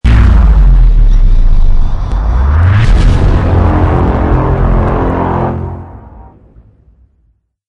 Futuristic_Weapons_Sound_Effects_-_heavy-beam-weapon.mp3